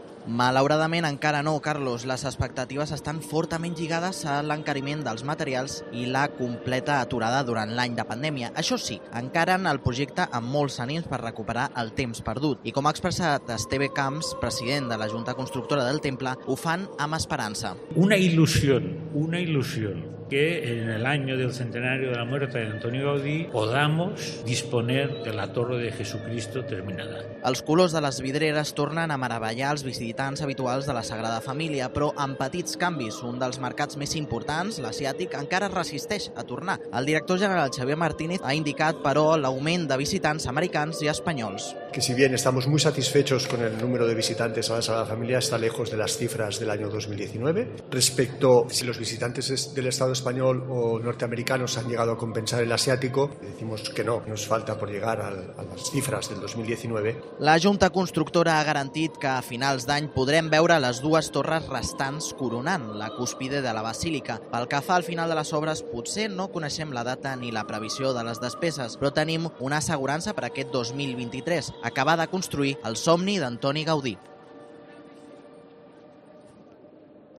crónica desde la Sagrada Familia